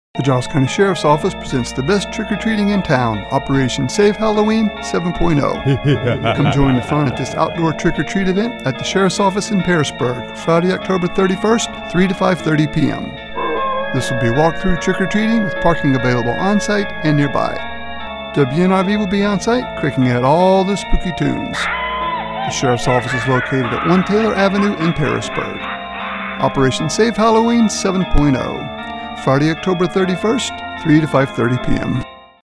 radio ad.